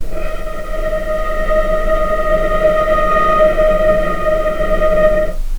vc-D#5-pp.AIF